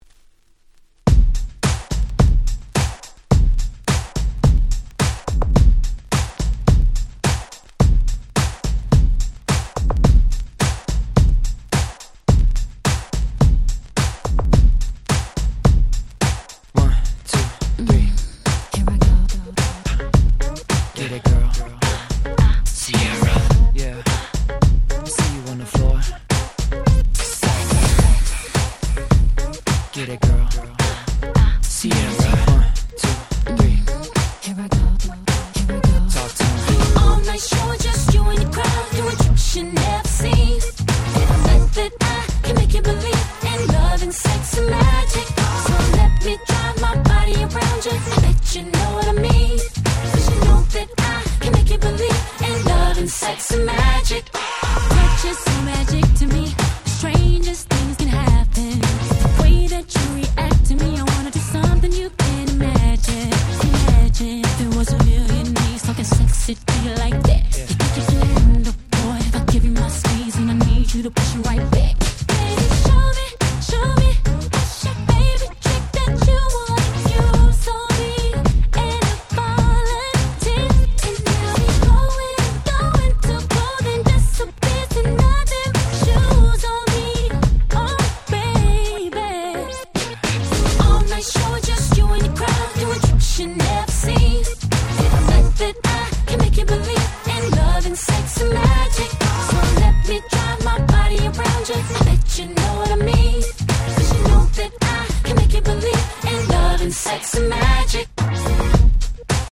自分が当時好んで使用していた曲を試聴ファイルとして録音しておきました。